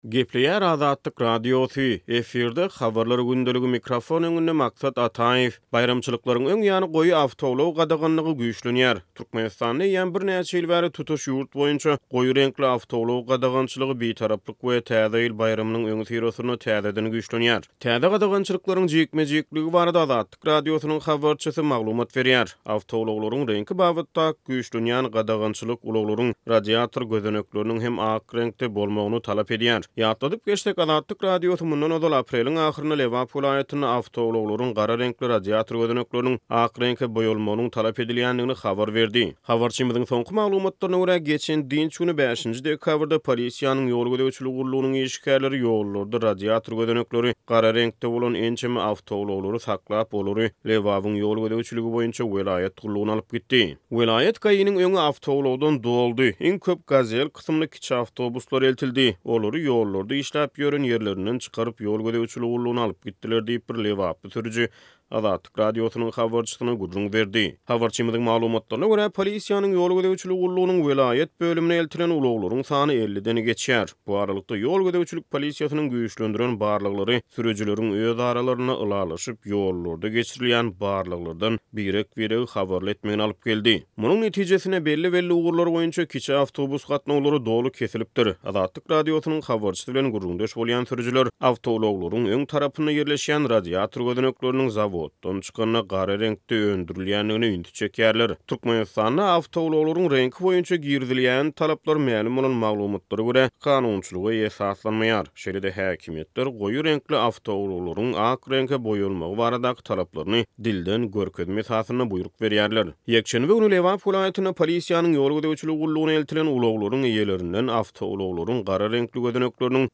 Türkmenistanda eýýäm birnäçe ýyl bäri tutuş ýurt boýunça goýy reňkli awtoulag gadagançylygy, Bitaraplyk we Täze ýyl baýramynyň öňüsyrasynda täzeden güýçlenýär. Täze gadagançylyklaryň jikme-jikligi barada Azatlyk Radiosynyň habarçysy maglumat berýär.